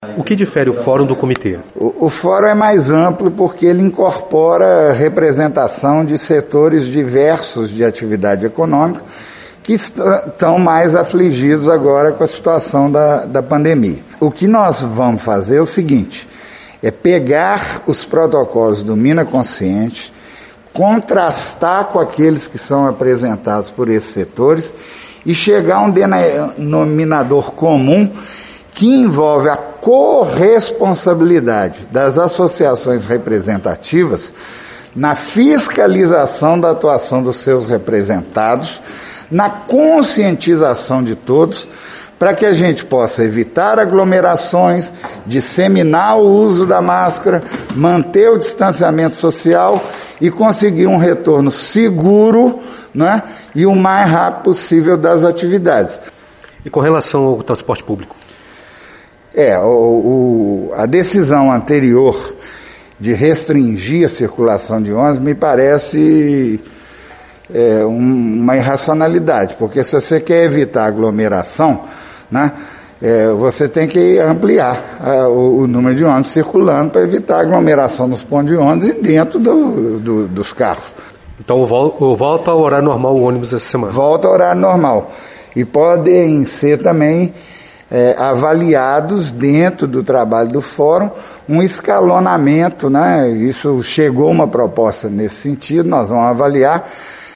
Um dos pontos práticos anunciados, nesta terça, foi o retorno integral da circulação de transporte coletivo urbano. O anúncio foi reforçado pelo secretário de Desenvolvimento Econômico, Turismo e Agropecuária, Ignácio Delgado, que também falou sobre o fórum.